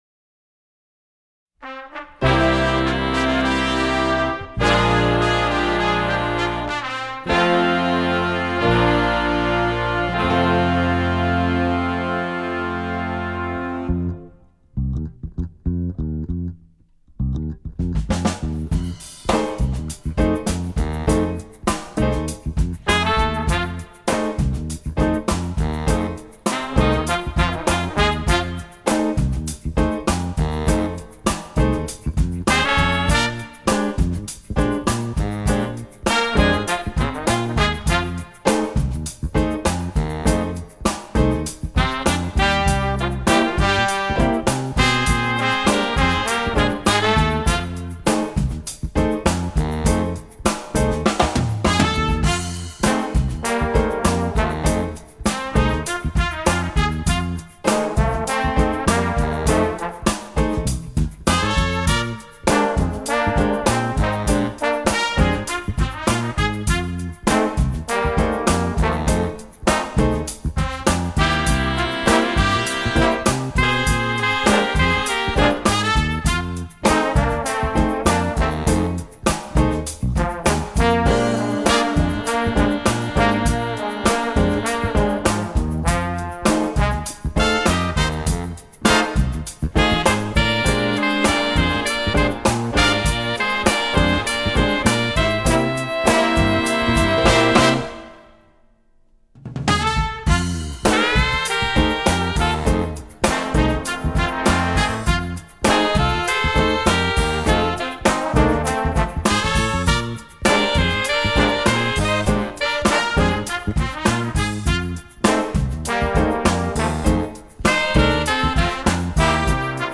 jazz, latin, rock